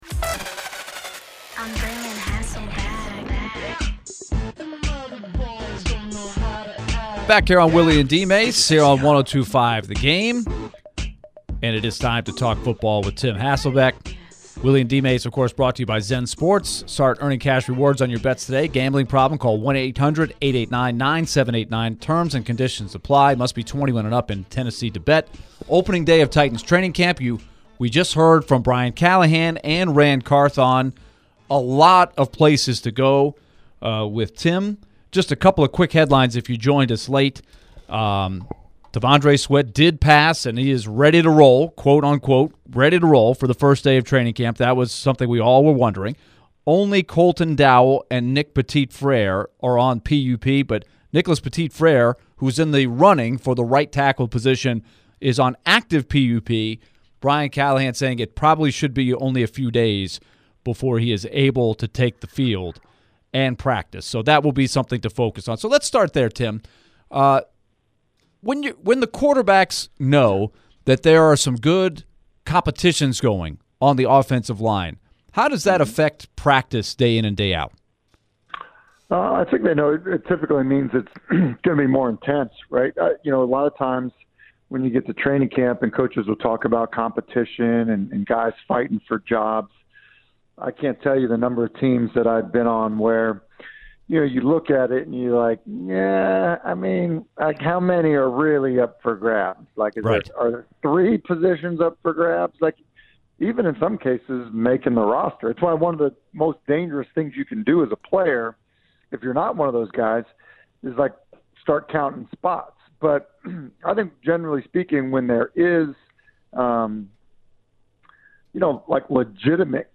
ESPN NFL Analyst Tim Hasselbeck joined the show and shared his thoughts on the Titans press conference. What does Tim think about the Titans wide receivers? Will the Titans look at resigning DeAndre Hopkins?